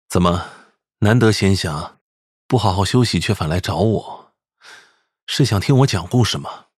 VOICE: Calm and soothing (